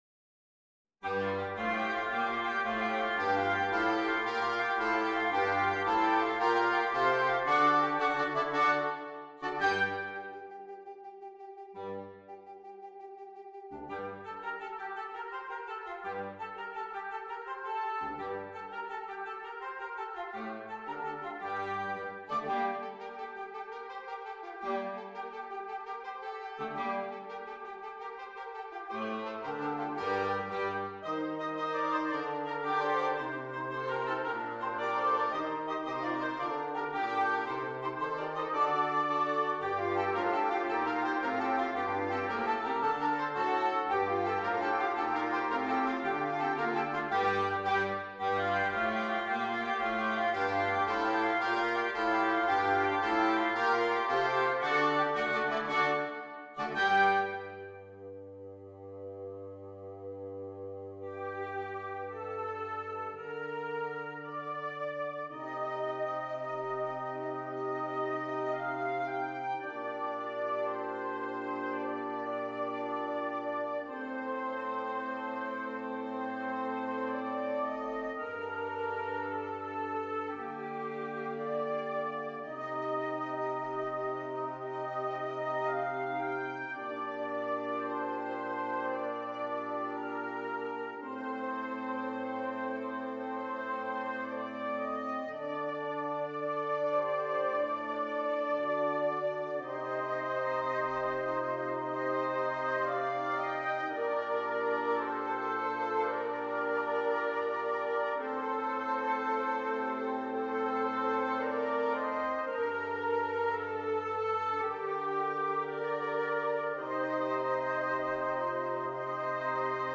Interchangeable Woodwind Ensemble
Great melodies and driving rhythms - argh!